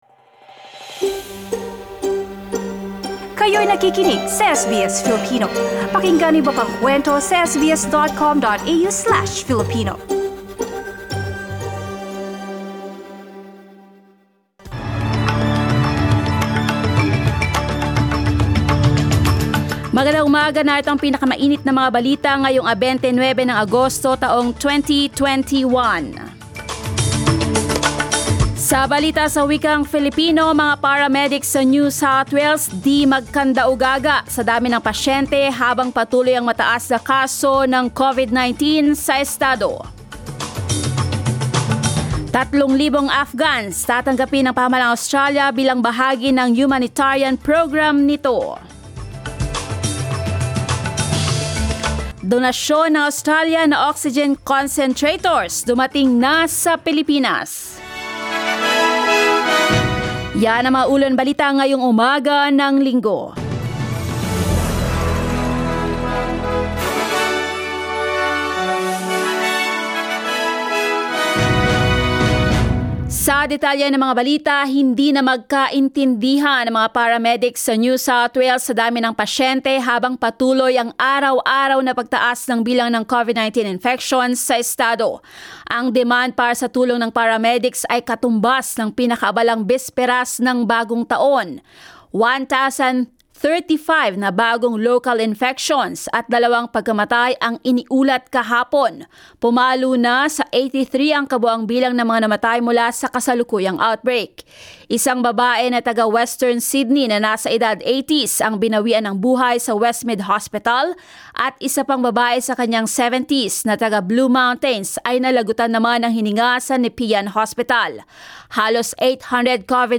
SBS News in Filipino, Sunday 29 August